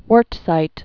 (wûrtsīt)